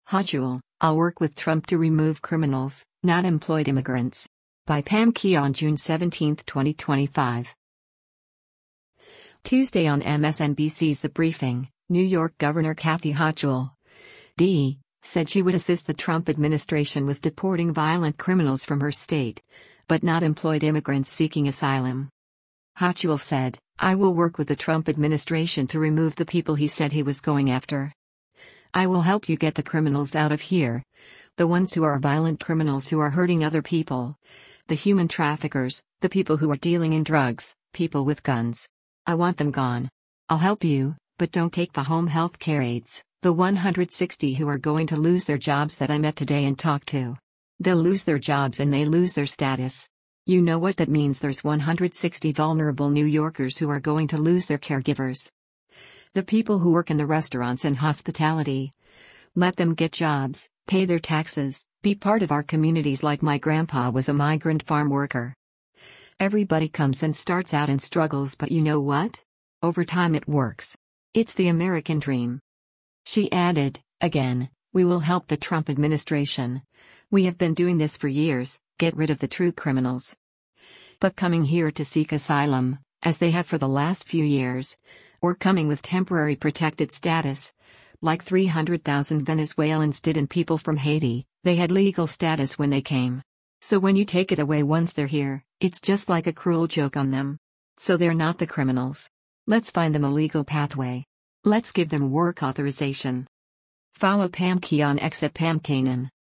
Tuesday on MSNBC’s “The Briefing,” New York Gov. Kathy Hochul (D) said she would assist the Trump administration with deporting violent criminals from her state, but not employed immigrants seeking asylum.